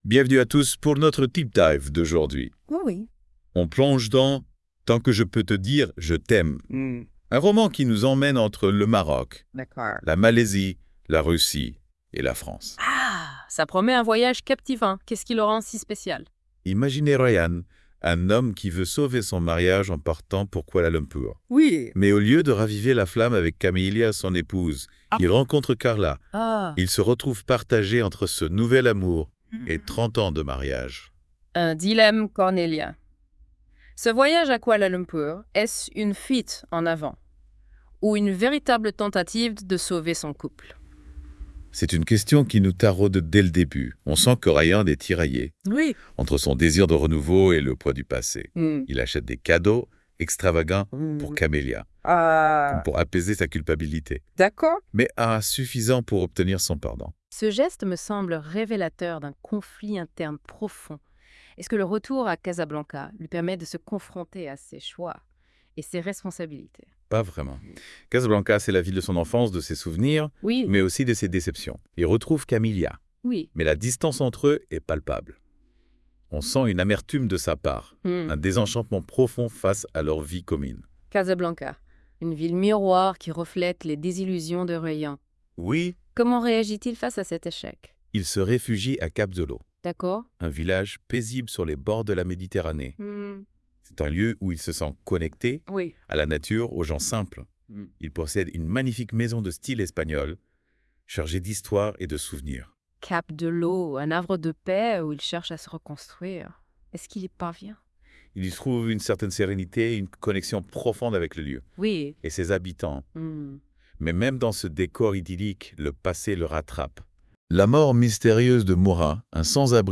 Nos chroniqueurs de la Web Radio R212 ont lus ce roman de Rida Lamrini , ils en discutent dans ce podcast